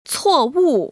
[cuòwù] 추오우  ▶